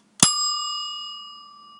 ding1.ogg